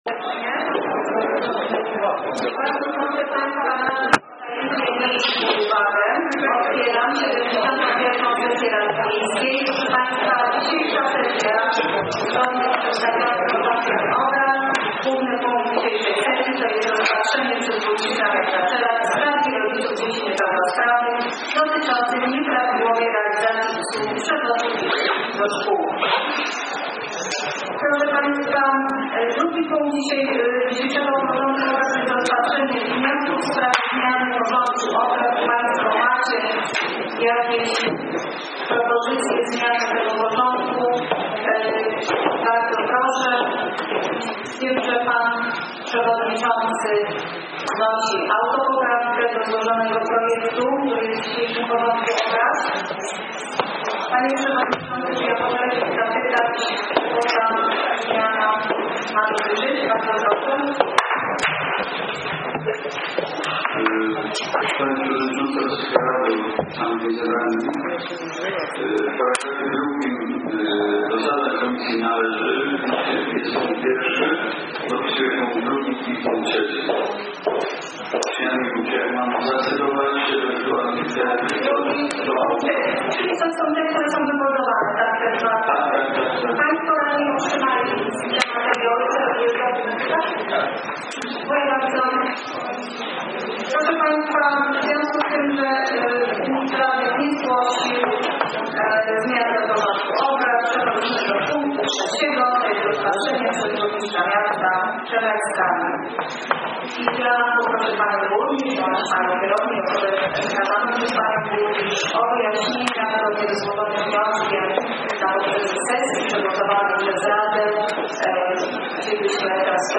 lista obecności na sesji 26 maja 2014 r. 170.5 KB 2014-05-29 karty z wynikami głosowania nad przyjęciem uchwał 353.0 KB 2014-05-29 Nagranie z posiedzenia Sesji Rady Miejskiej Nr LXXI 26.05.2014 Odtwórz 25,511.2 KB 2014-05-29